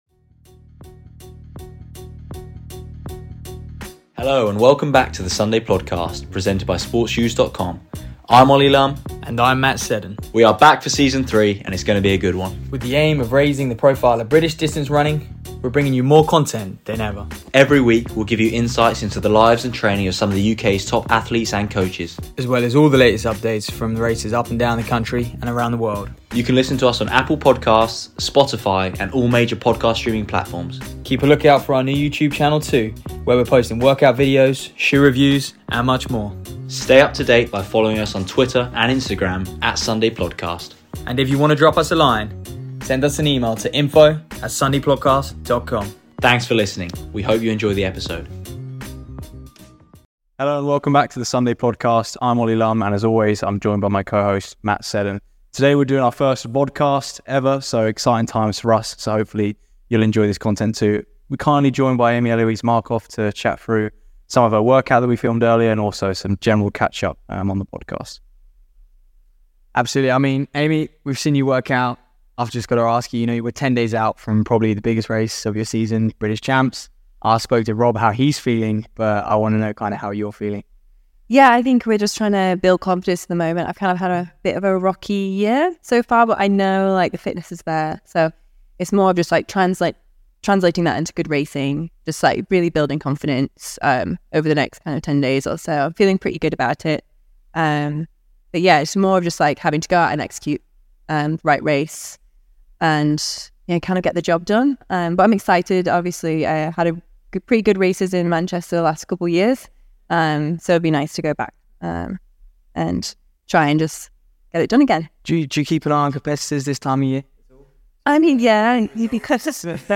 We were joined by On athlete, Amy-Eloise Markovc for this episode, as she was preparing for this weekend's British Championships.